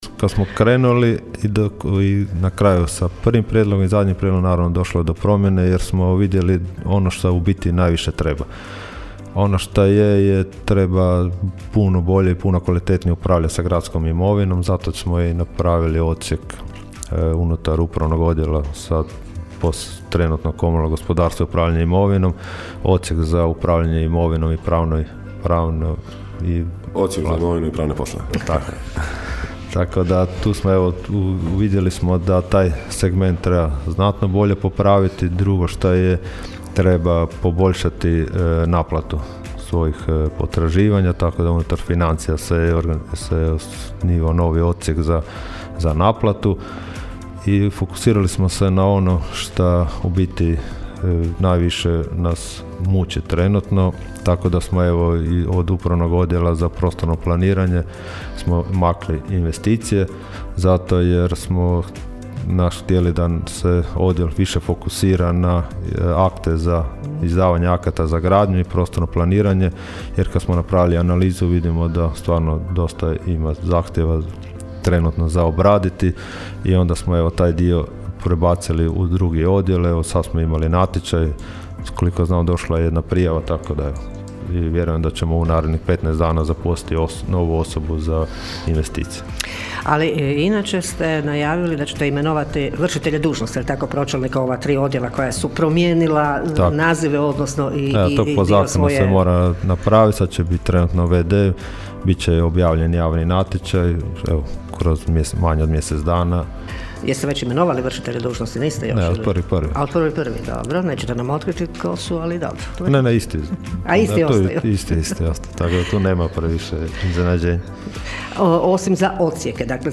Na posljednjoj sjednici Gradskog vijeća Labina usvojena je odluka o novom ustrojstvu gradske uprave. O tome su u subotu u Gradskim minutama govorili gradonačelnik Donald Blašković i njegov zamjenik Goran Vlačić: (